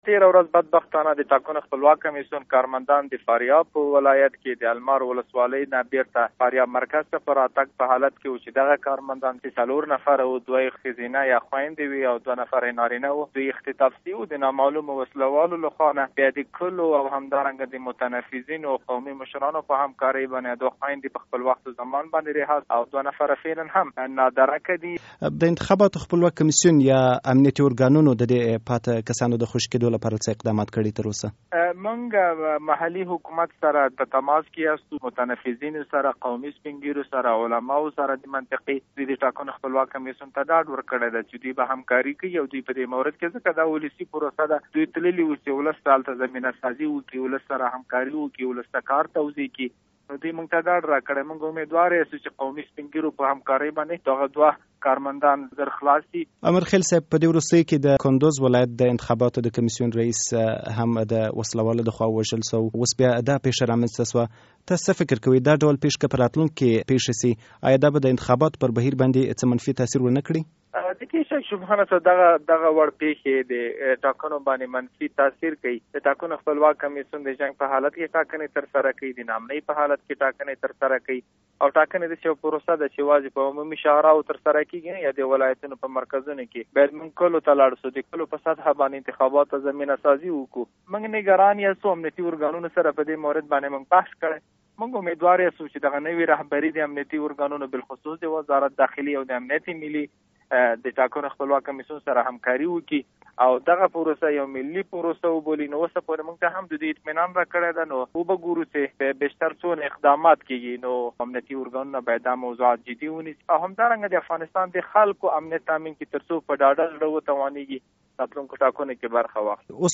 د دارلانشا له مشر ضیا الحق امر خیل سره مرکه